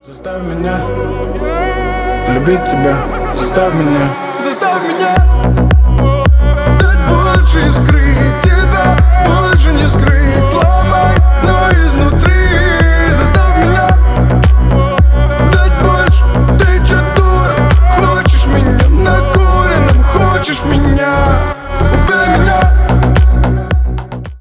• Качество: 320, Stereo
поп
мужской вокал
deep house
басы
качающие